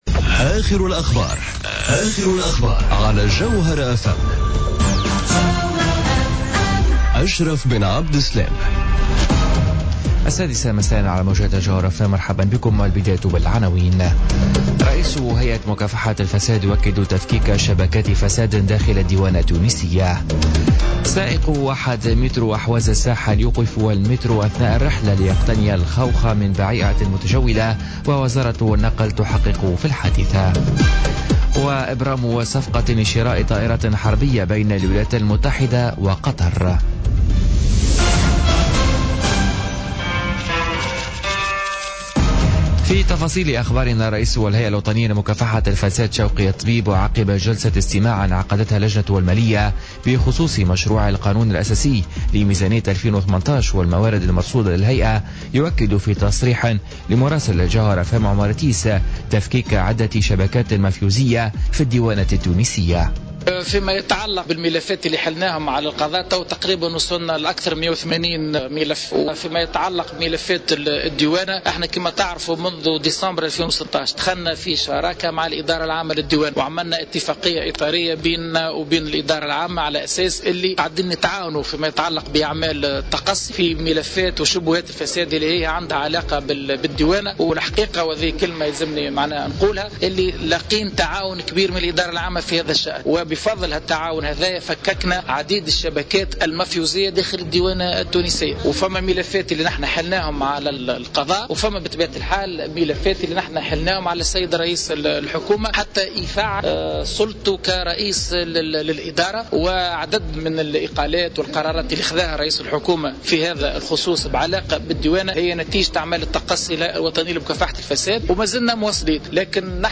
نشرة أخبار السادسة مساء ليوم الخميس 15 جوان 2017